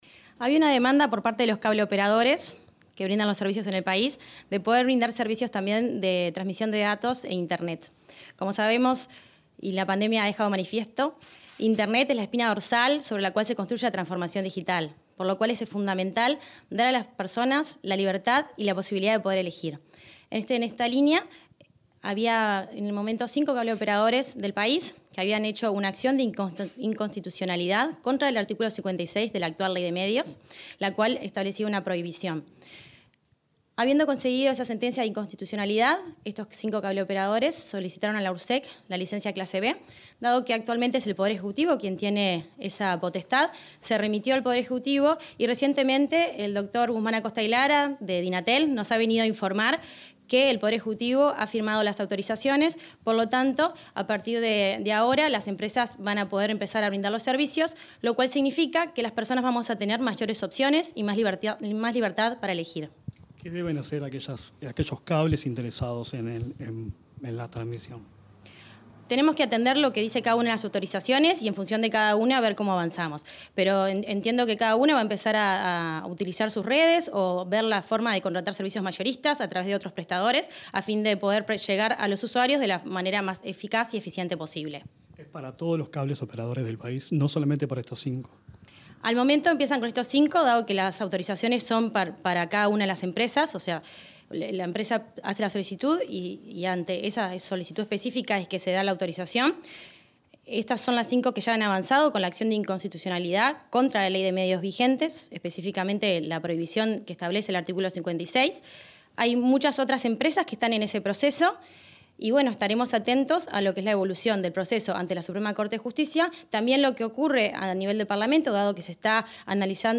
Entrevista a la presidenta de Ursec, Mercedes Aramendía